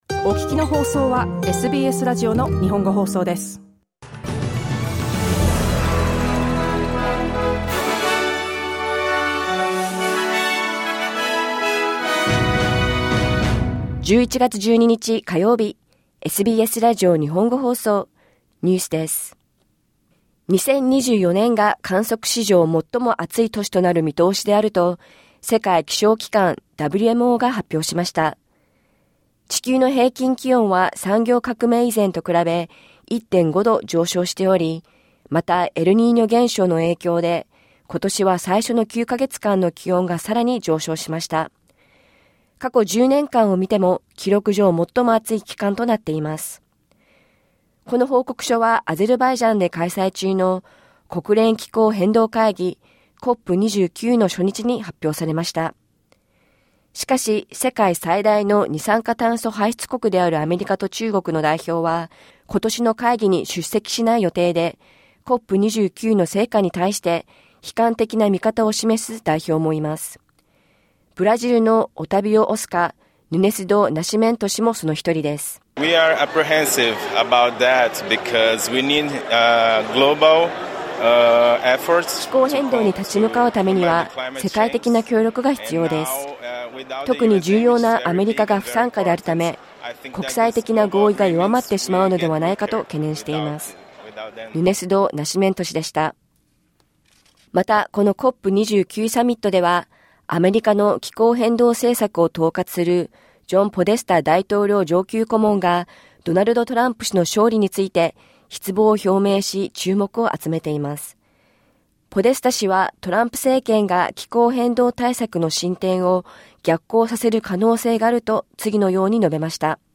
世界気象機関は、2024年が観測史上最も暑い年となり、災害の危機が迫っていると警告しています。午後１時から放送されたラジオ番組のニュース部分をお届けします。